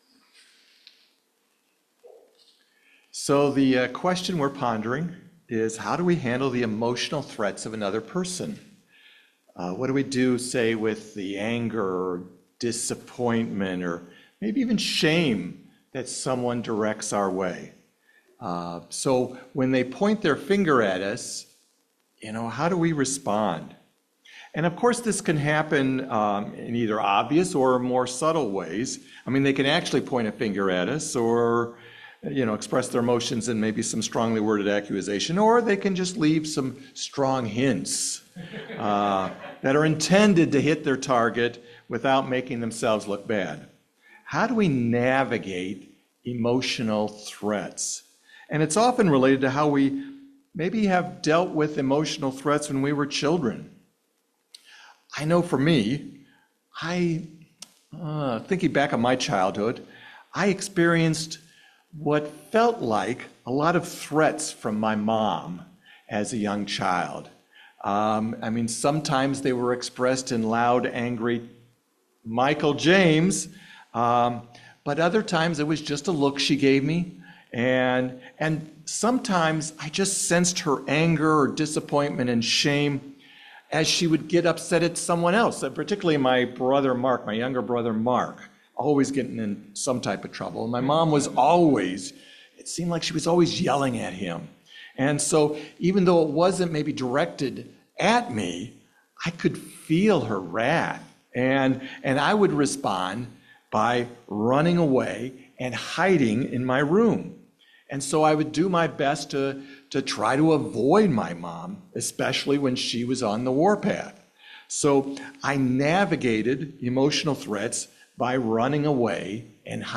Let Them – Phoenix Community Church UCC